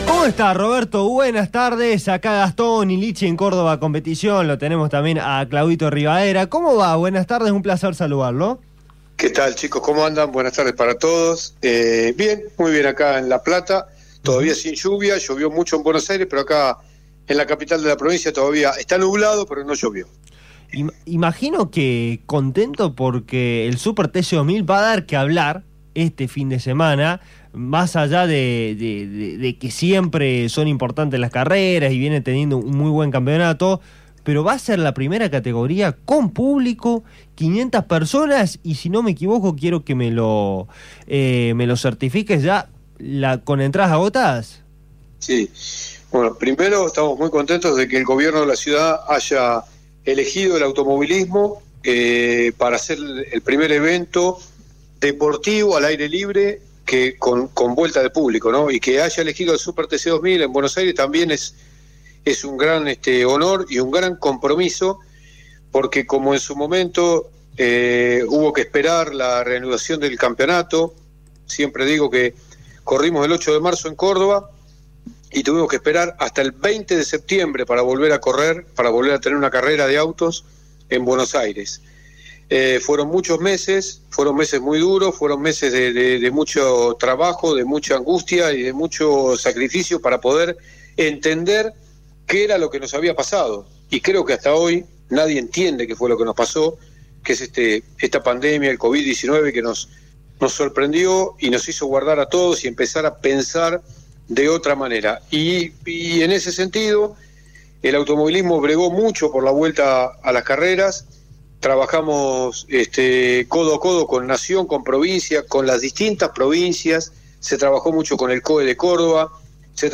En la entrevista se tocaron diversos temas, además de la noticia del regreso de los espectadores, como lo es la continuidad del calendario 2020 y 2021, entre muchos otros.